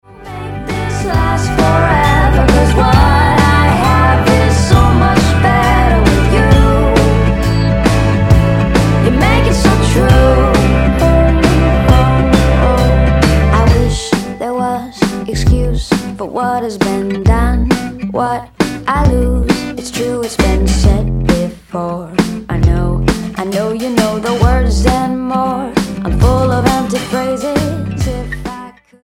A sister act
Style: Pop